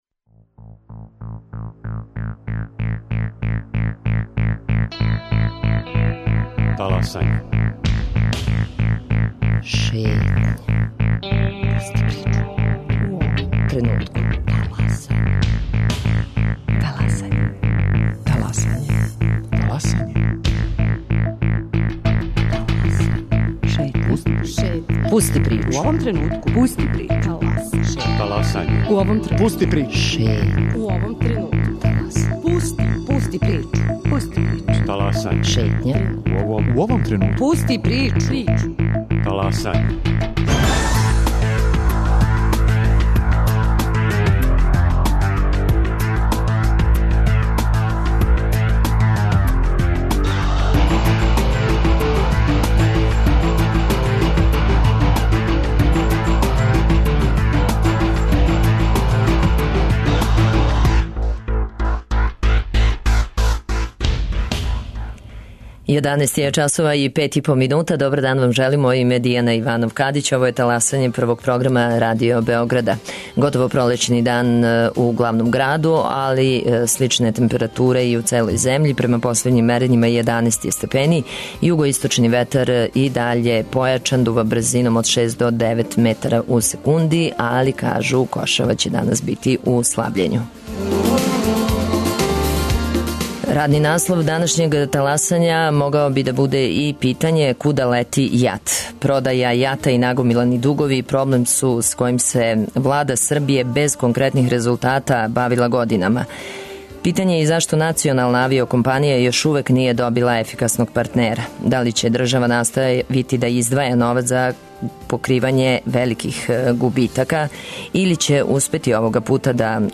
Радио Београд 1, 11.05